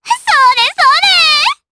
Pansirone-Vox_Attack4_jp.wav